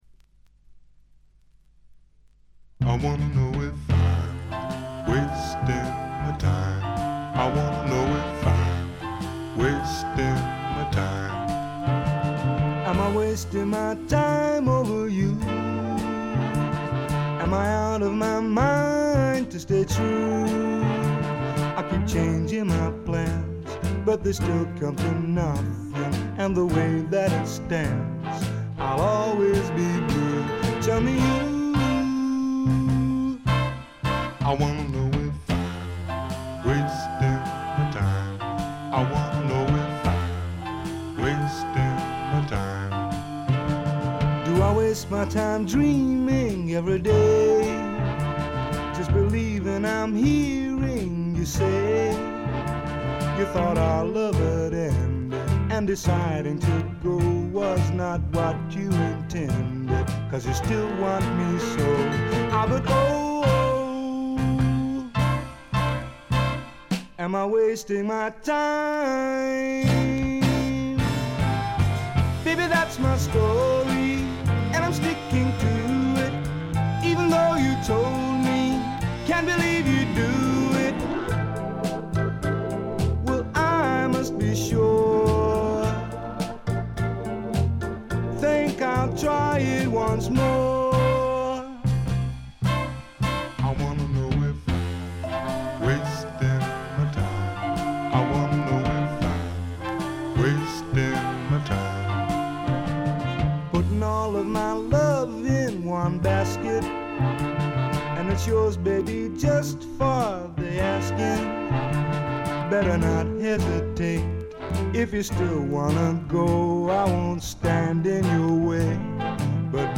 わずかなチリプチ。散発的なプツ音が数回。
試聴曲は現品からの取り込み音源です。